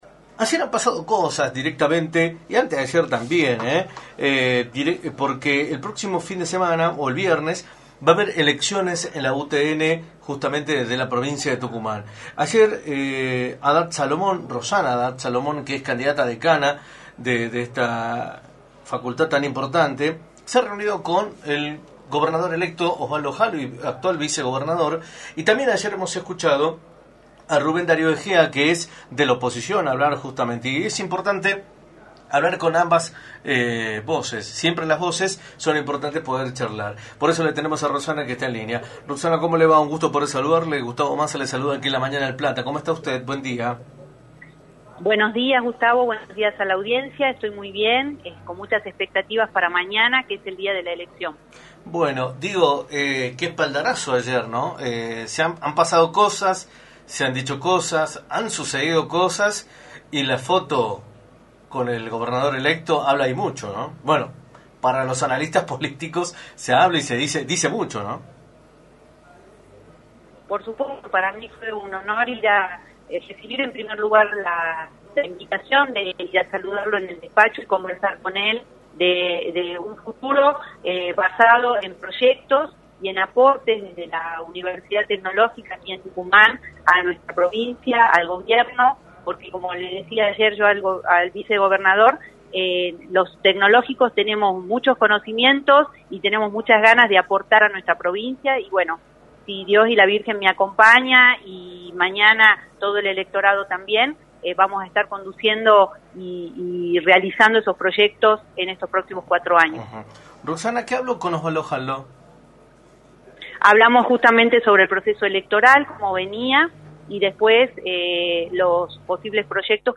entrevista para “La Mañana del Plata”, por la 93.9.